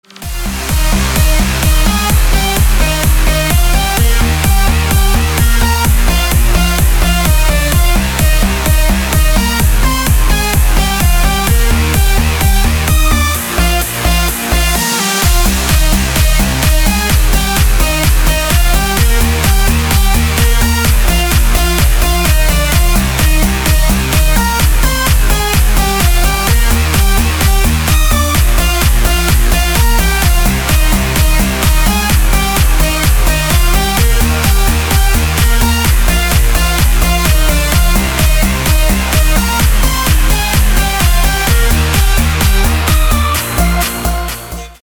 • Качество: 320, Stereo
громкие
dance
Electronic
электронная музыка
без слов
Big Room
house